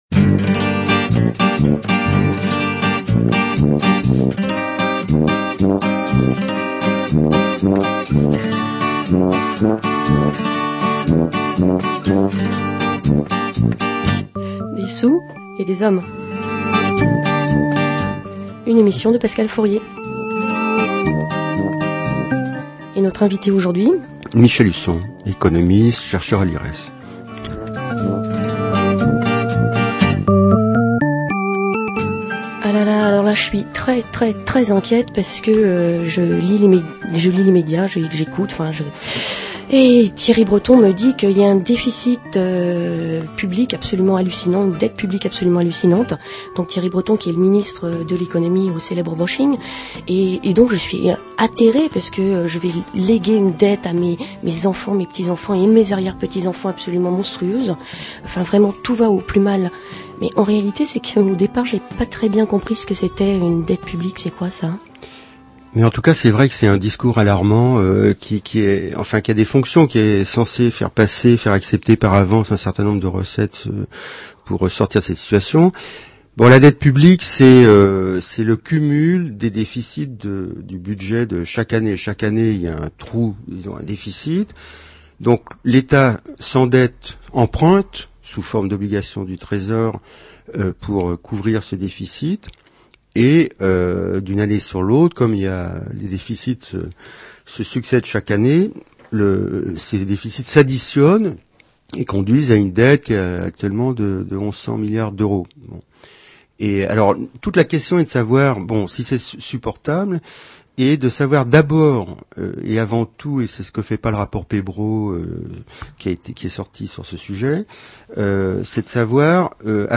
L’émission radiophonique (au format mp3)